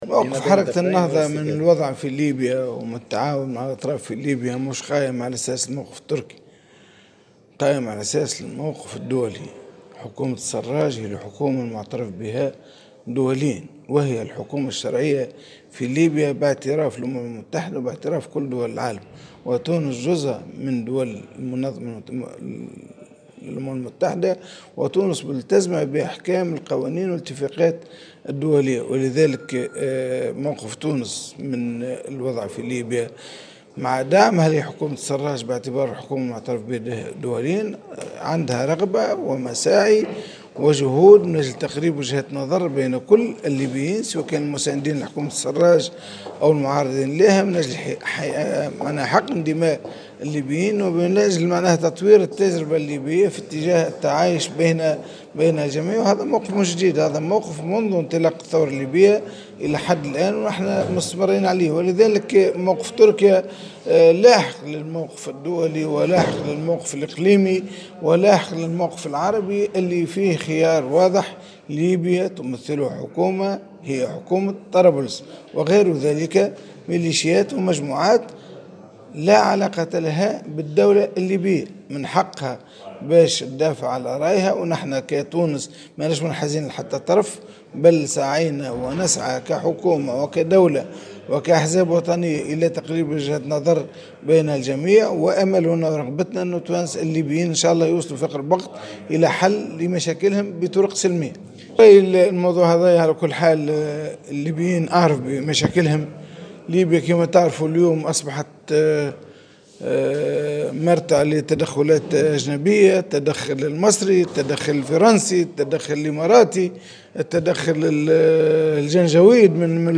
C'est dans une déclaration accordée à Jawhara FM que M. Bhiri a réitéré que le gouvernement d'Union nationale de Fayez El Sarraj est le gouvernement légitime vu qu'il est reconnu par les Nations-Unies et que la Tunisie fait partie de l'ONU.